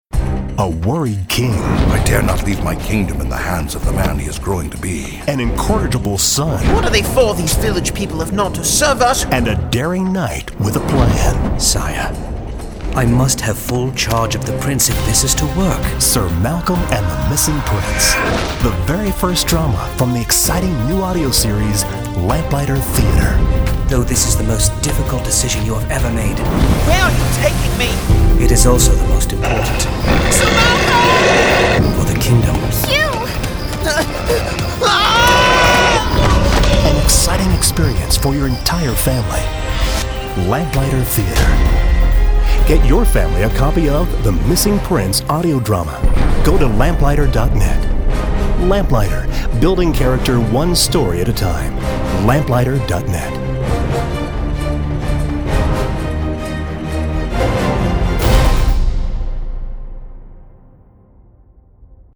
Sir Malcolm and the Missing Prince – Dramatic Audio CD